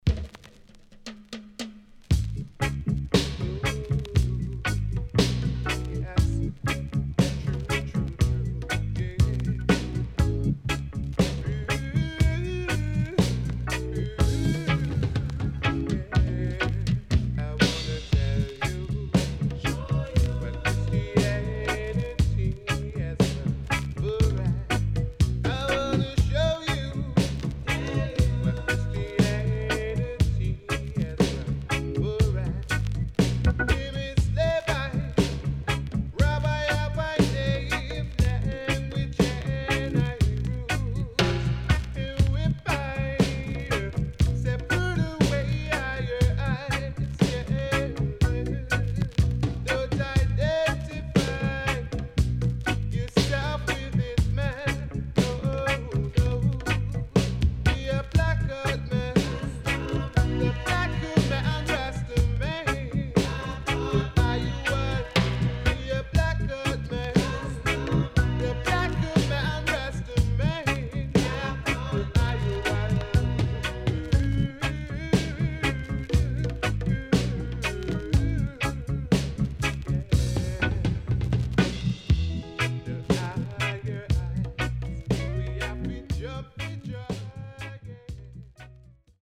HOME > LP [VINTAGE]  >  KILLER & DEEP
SIDE A:所々チリノイズがあり、少しプチノイズ入ります。